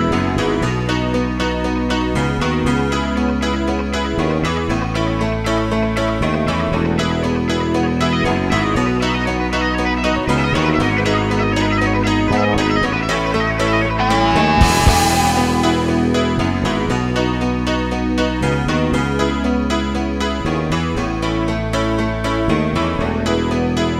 Two Semitones Down Rock 4:28 Buy £1.50